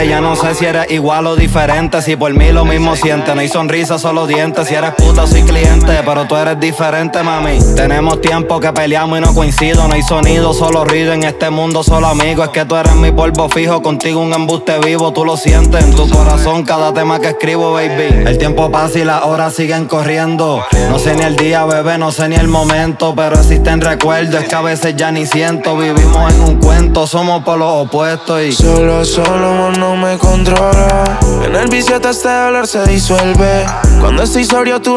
Urbano latino